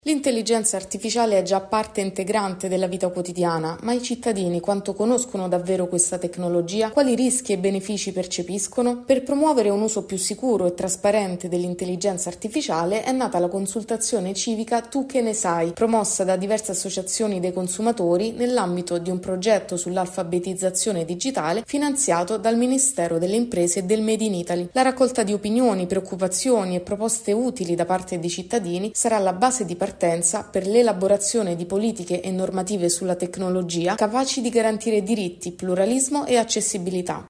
Tu che ne s-ai? – Al via una consultazione civica sull’intelligenza artificiale e i diritti dei consumatori. Il servizio